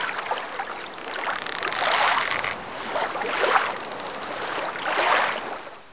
1 channel
dock1.mp3